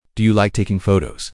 Part 1 (Introduction & Interview)